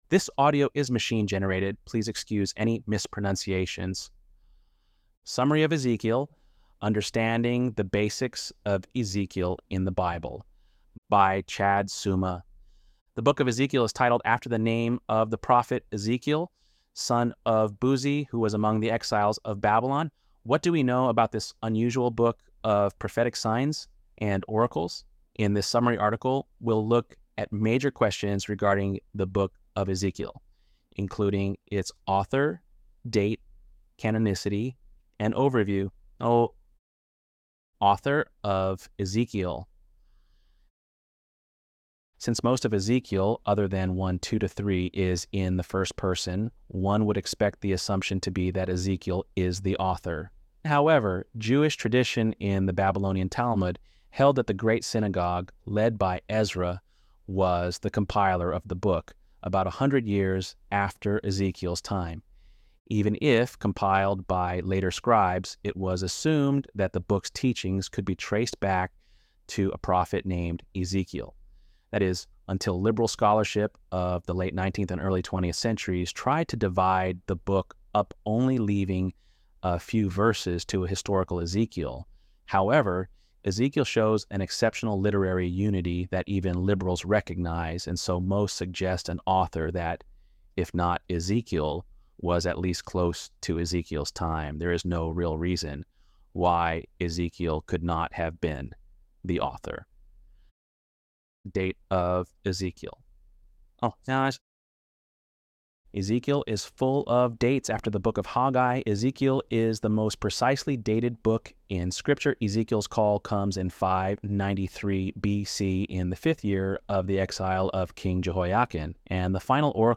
ElevenLabs_7.23_Ezekiel.mp3